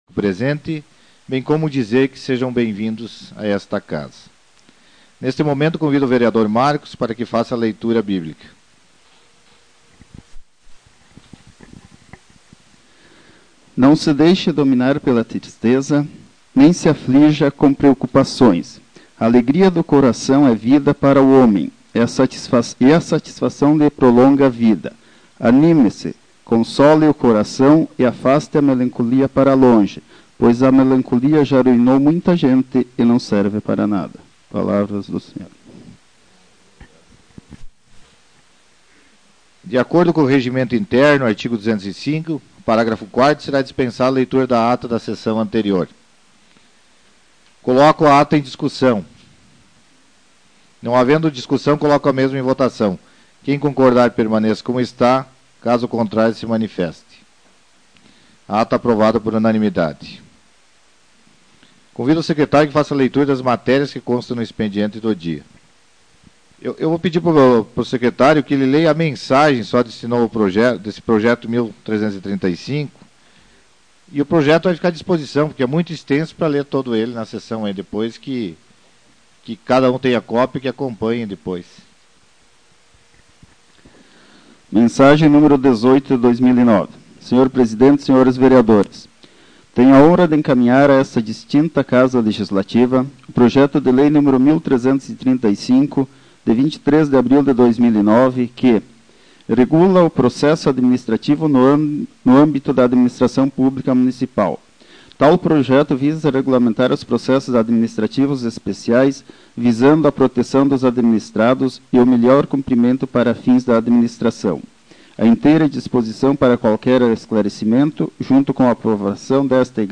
Sessão Ordinária de 2009